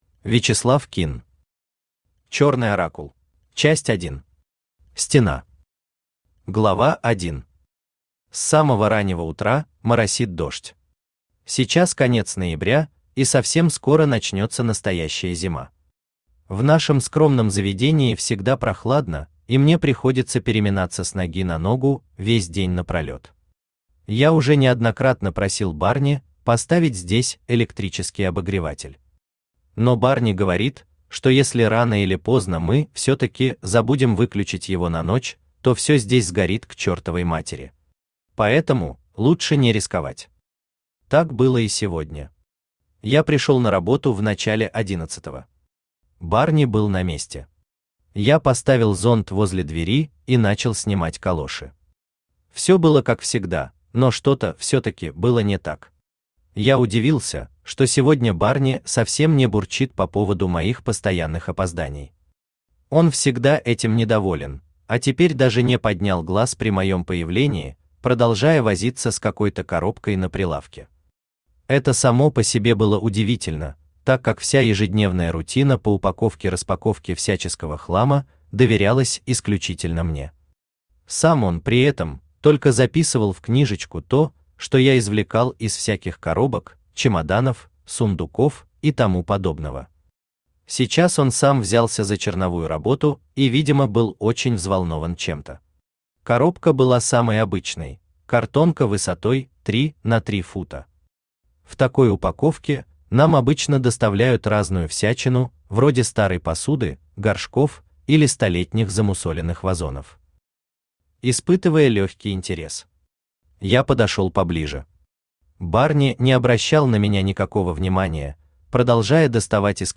Аудиокнига Черный Оракул | Библиотека аудиокниг
Aудиокнига Черный Оракул Автор Вячеслав Кинн Читает аудиокнигу Авточтец ЛитРес.